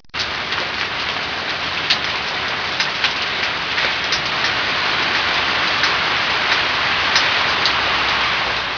Free Sound Effects
Rain.mp3